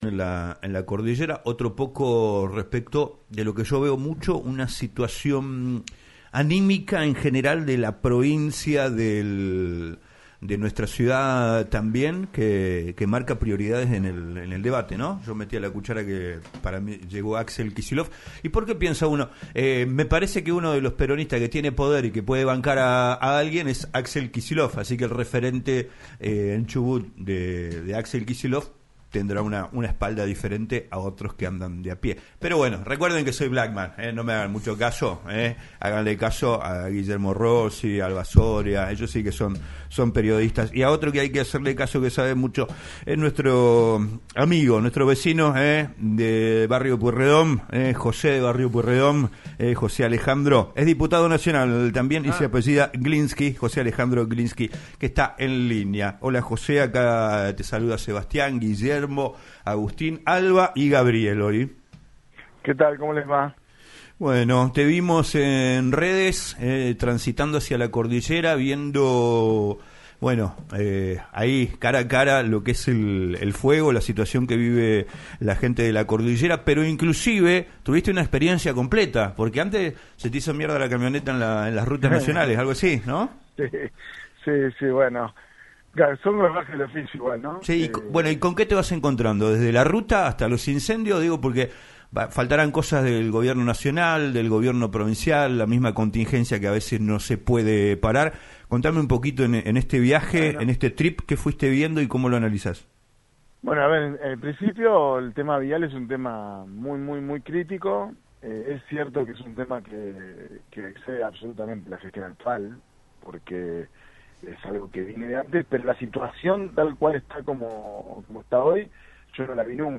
El diputado nacional por el bloque de Unión por la Patria, José Glinski, recorrió las zonas afectadas por los incendios en la provincia de Chubut y en diálogo con LaCienPuntoUno relató lo angustiante que es la situación con consecuencias devastadoras tras reavivarse el fuego en el Parque Nacional Los Alerces.